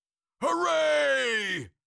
Cartoon Lion, Voice, Hooray Sound Effect Download | Gfx Sounds
Cartoon-lion-voice-hooray.mp3